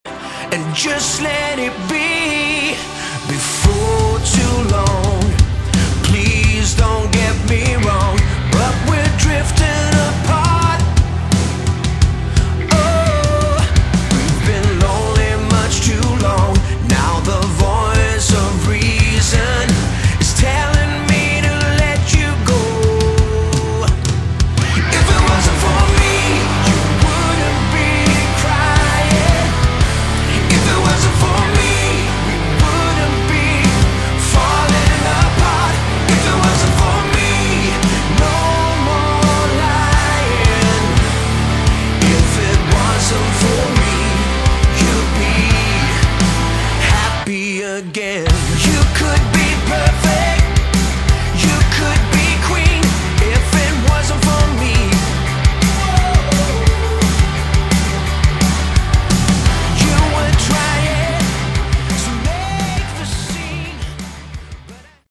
Category: Melodic Rock
Vocals, Bass
Drums
Keyboards
Guitars